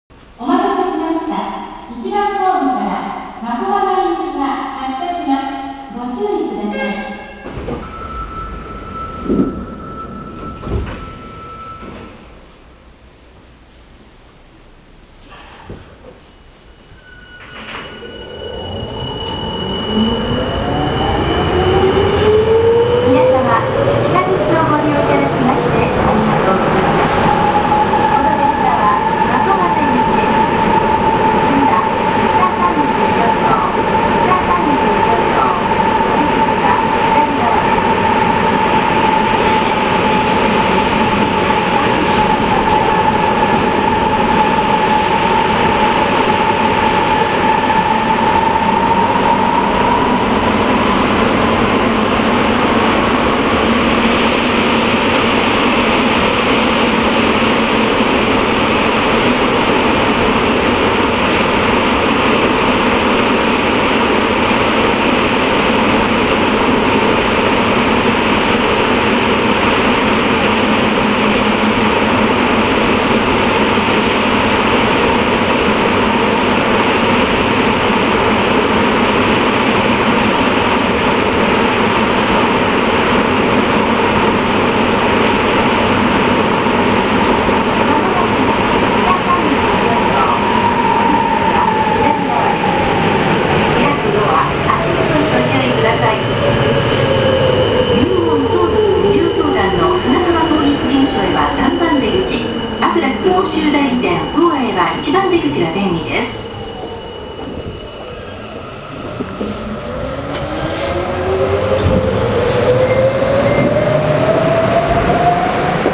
・5000形（東芝IGBT）走行音
【南北線】麻生〜北34条（1分55秒：630KB）…収録は5105Fにて。
5000形のうち、5104、5105、5116、5117の４本の編成は東芝IGBTを搭載しています。日立3レベルIGBTの音にとてもよく似ていますが、若干こちらの方が起動音の音程が低くなっています。